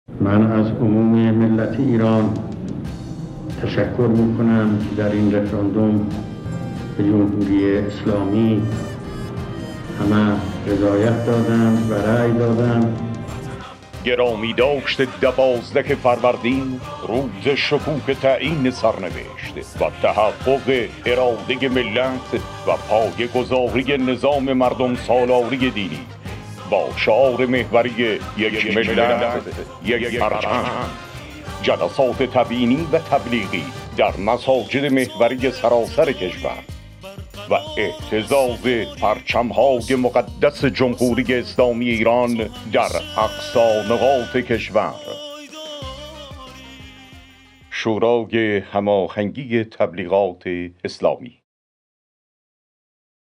تیزر اطلاع رسانی مراسم گرامیداشت یوم الله دوازدهم فروردین